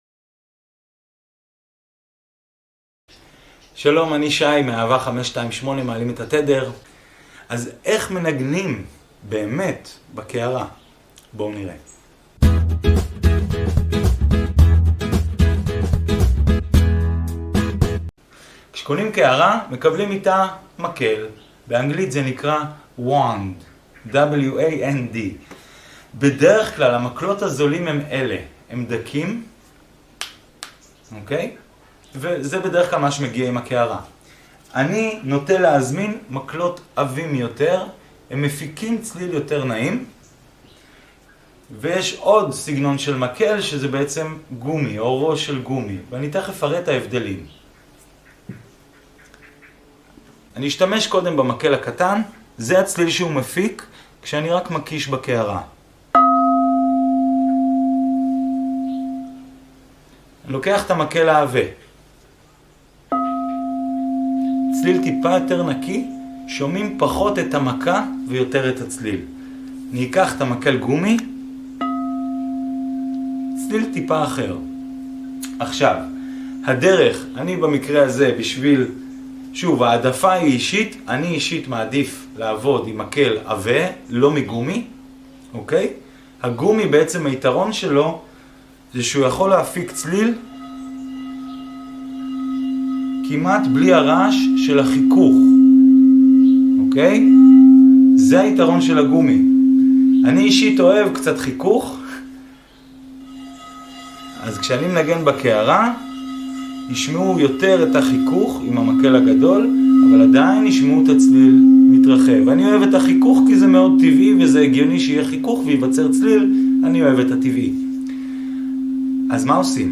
how to play and how to tune crystal bowls ? part 2